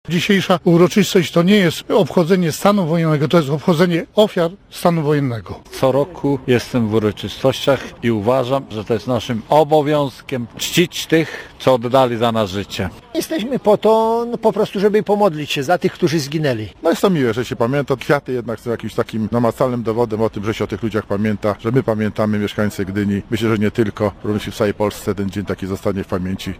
Posłuchaj uczestników wydarzenia:
44_stanu_wojennego_gdynia_sonda.mp3